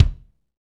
Index of /90_sSampleCDs/Northstar - Drumscapes Roland/KIK_Kicks/KIK_Funk Kicks x
KIK FNK K05R.wav